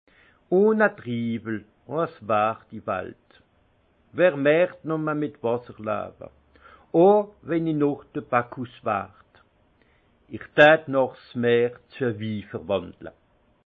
Haut Rhin
Ville Prononciation 68
Munster